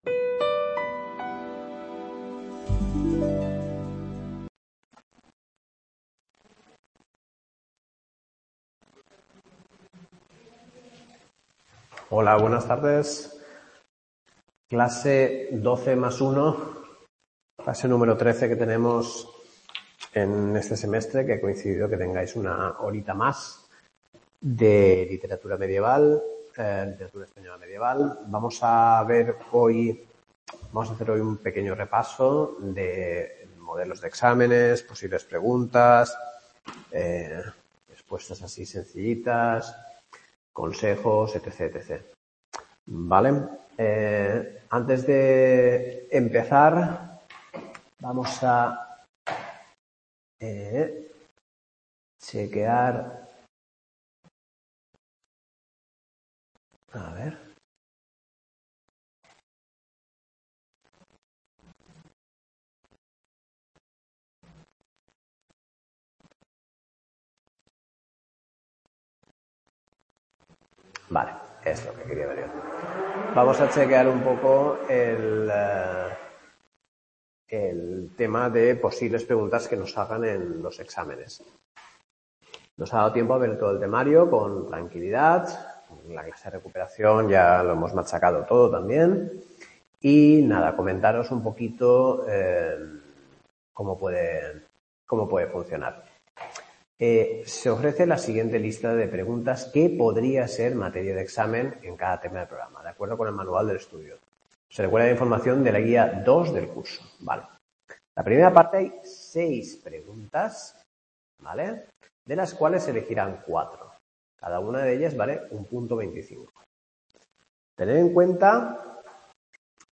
CLASE FINAL LITERATURA MEDIEVAL | Repositorio Digital